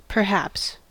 Ääntäminen
RP : IPA : /pəˈhæps/ GenAm: IPA : /pɚˈhæps/